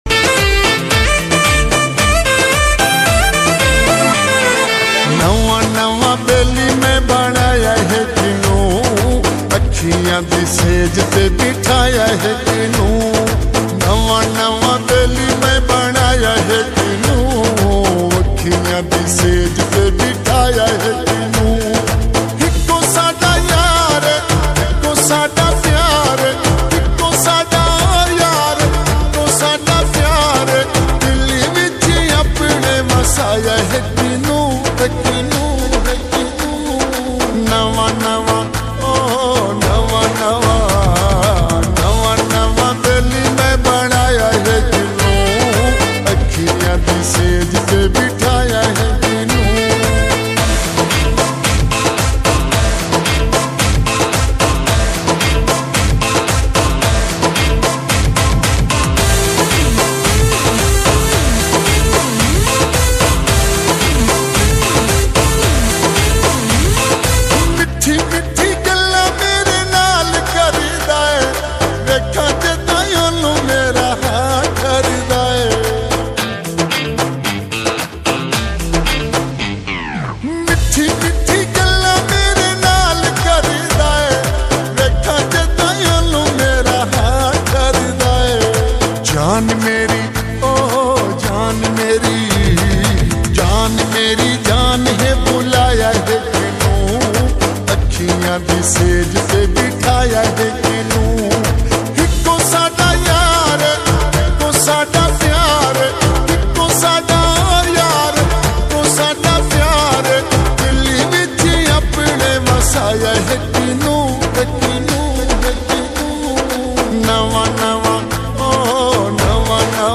slow reverb